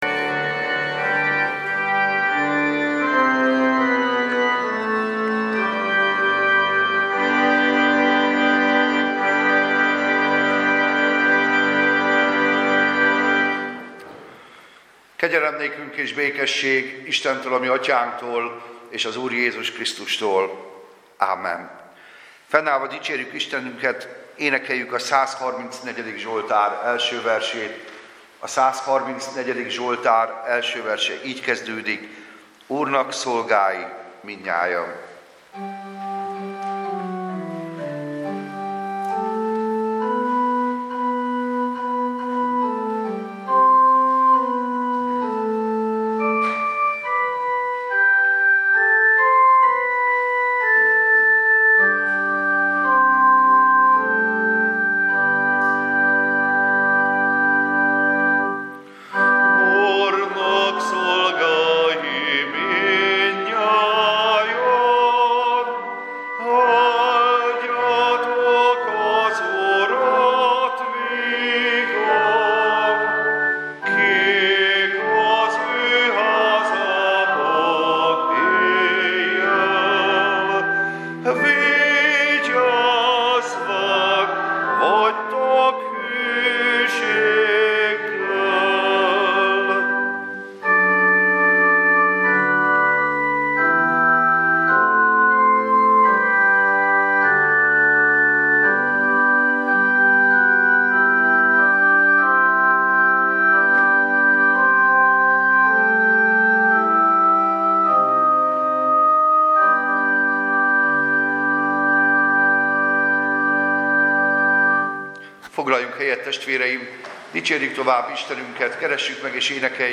Service Type: Igehirdetés